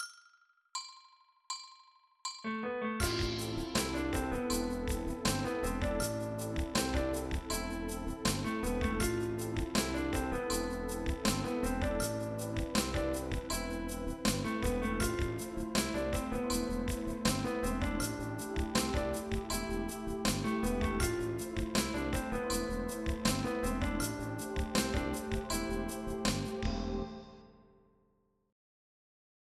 Ogólna zasada jest podobna jak w przykładzie 3 - mamy zdanie złożone: tension (F) -> tension (B) -> tension (D) -> release (E):
* Zwróćcie uwagę, że mamy tutaj dwa razy dźwięki "kluczowe" (B, D) zagrane nie na moce wartości rytmiczne, ale z wyprzedzeniem (16-tkowym).